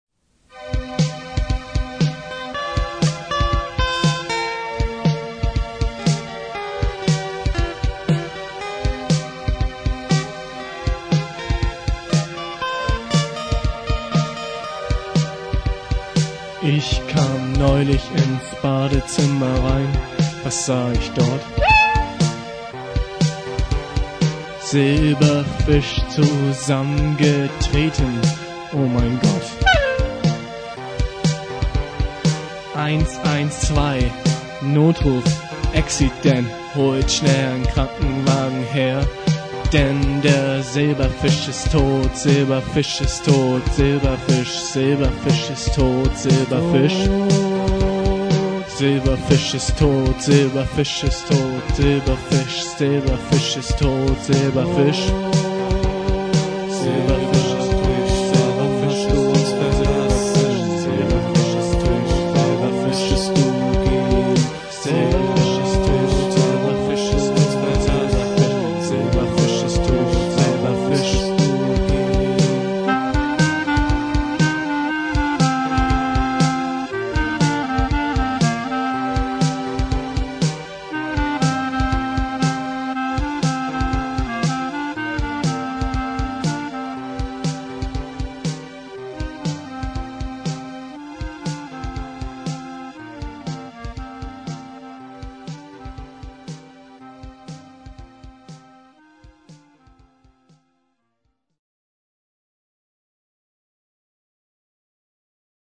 Anti-folk Indie-Pop from 1992 – 1995.